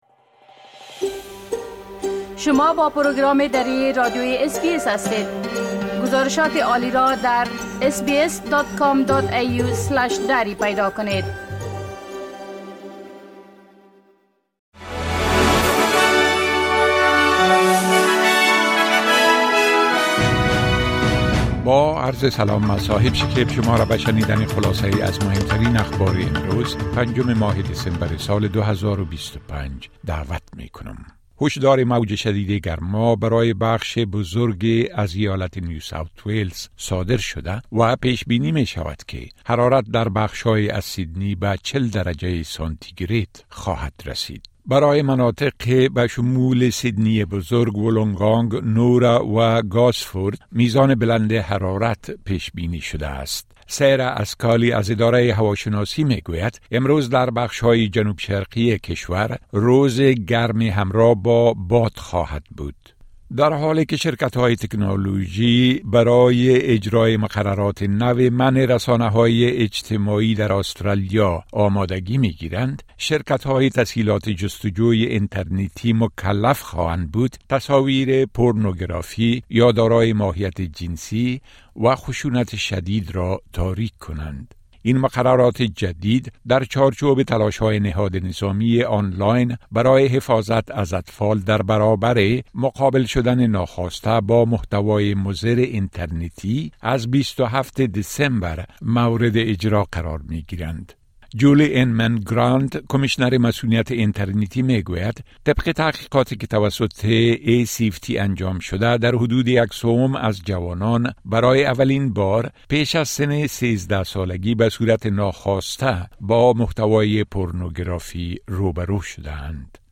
خلاصه مهمترين خبرهای روز از بخش درى راديوى اس‌بى‌اس